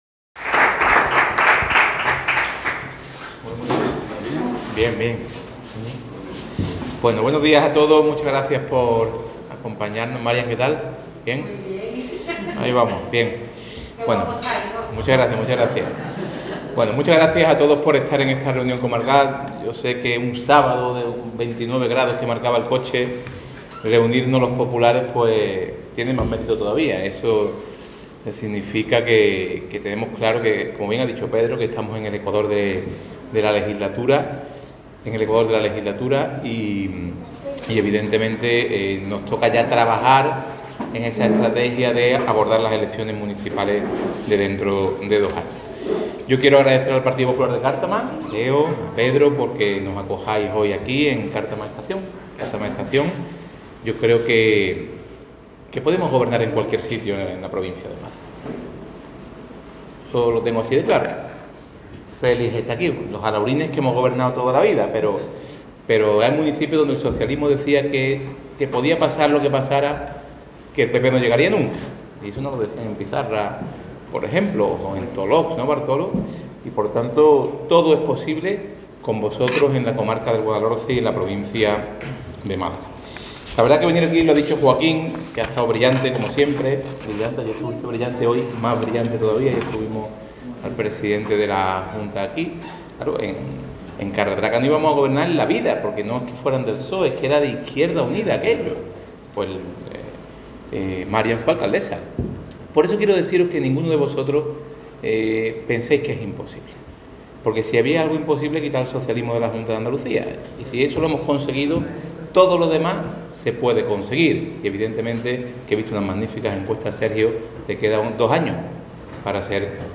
Bendodo ha hecho estas declaraciones en Cártama (Málaga), donde ha participado en una reunión de representantes del PP en la comarca del Valle del Guadalhorce.